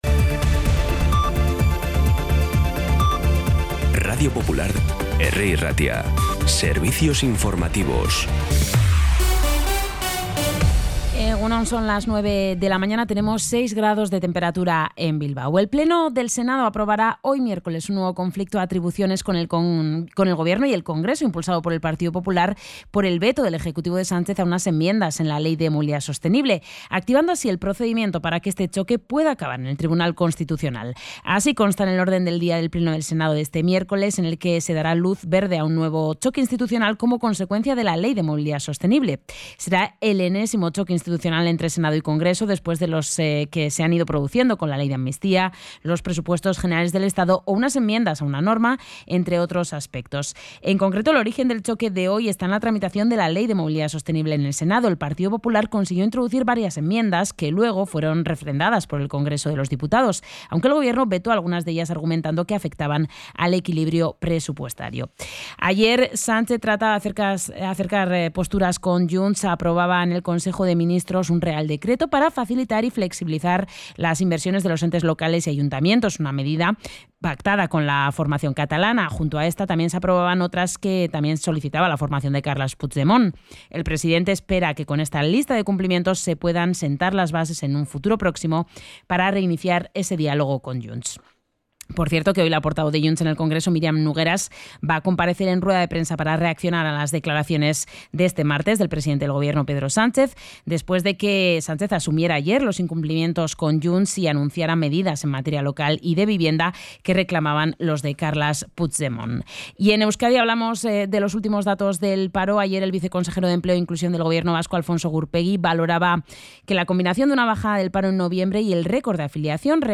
Las noticias de Bilbao y Bizkaia de las 9 , hoy 3 de diciembre
Los titulares actualizados con las voces del día. Bilbao, Bizkaia, comarcas, política, sociedad, cultura, sucesos, información de servicio público.